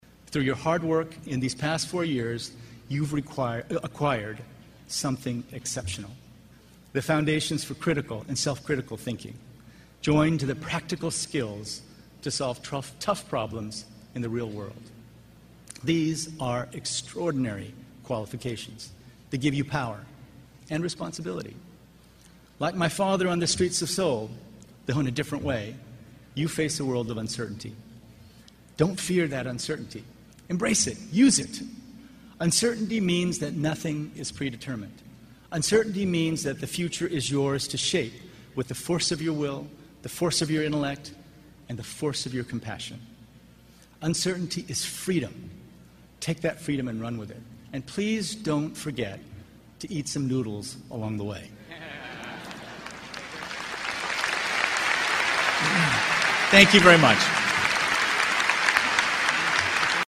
公众人物毕业演讲 第74期:金墉美国东北大学(13) 听力文件下载—在线英语听力室